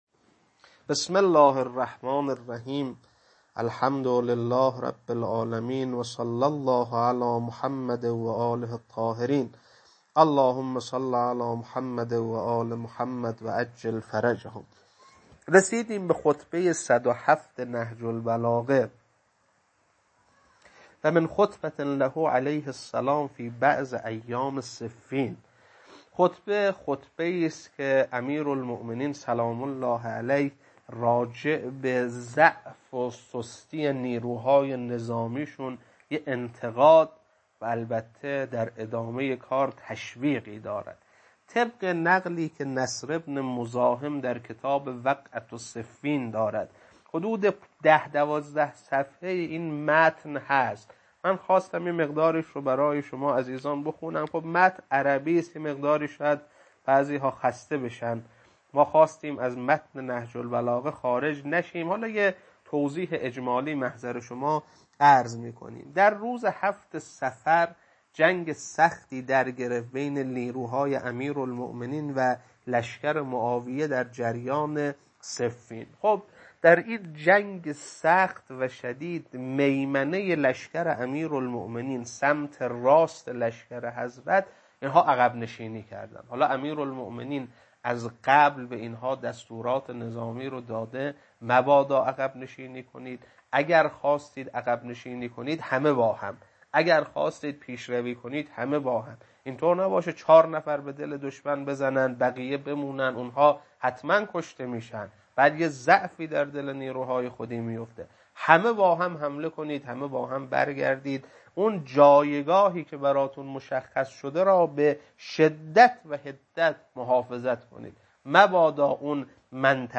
خطبه 107.mp3